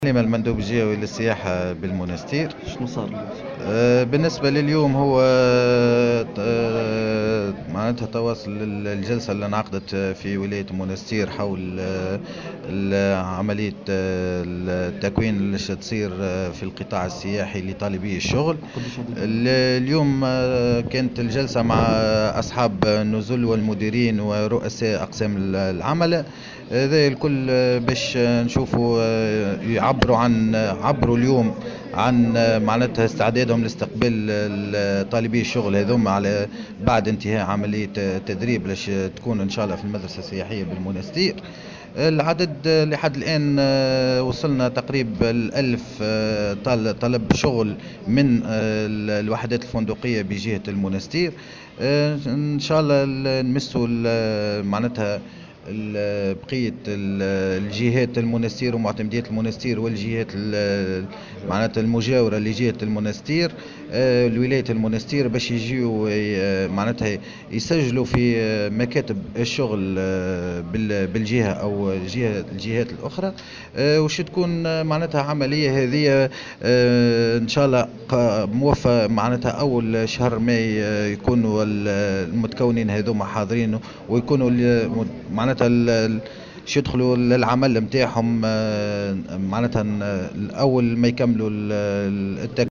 وقال في تصريح لمراسل "الجوهرة أف أم" على هامش اجتماع، إن أصحاب النزل والمديرون ورؤساء أقسام العملة أكدوا خلال الاجتماع استعدادهم لتوفير فرص العمل لطالبي الشغل وتدريبهم ليتم لاحقا تشغيلهم مباشرة بداية من شهر ماي المقبل.